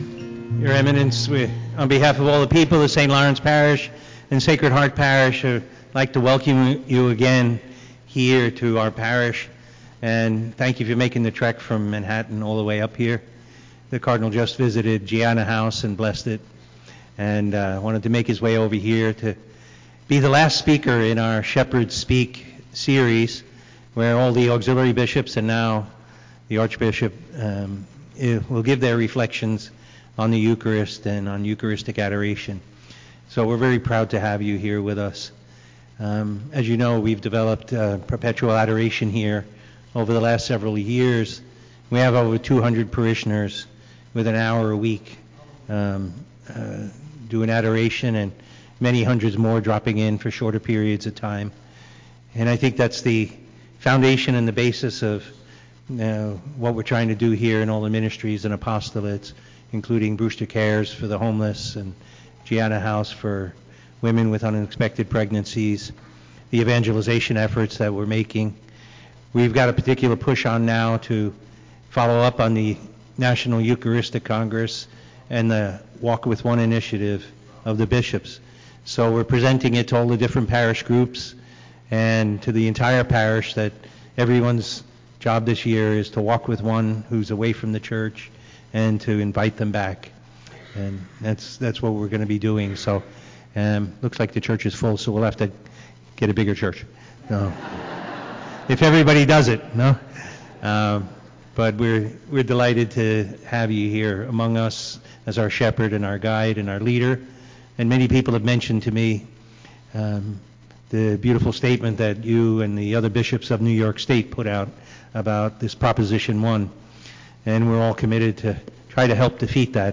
Spiritual Talk: Cardinal Timothy Dolan - St Lawrence - Sacred Heart
Cardinal Dolan’s visit to our parish, the last in the Shepherd’s Speak series, focused on the power of the Eucharist.